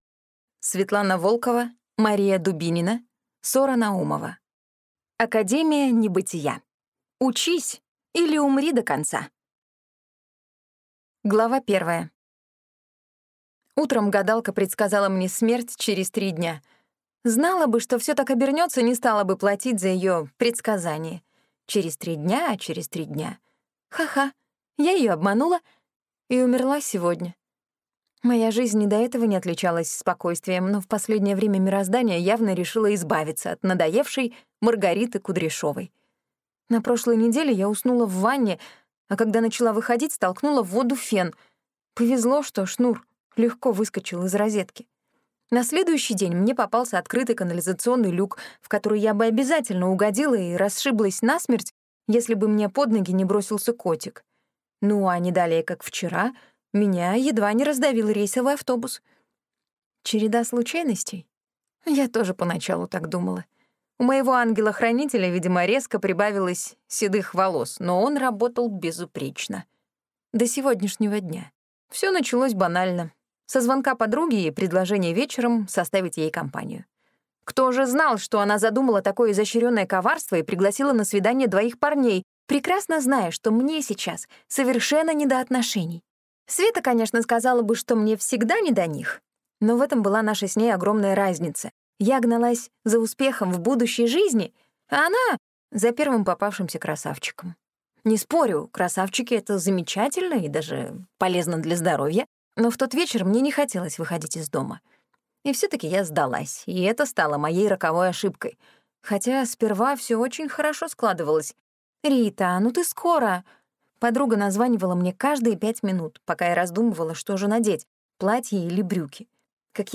Аудиокнига Академия небытия. Учись или умри до конца | Библиотека аудиокниг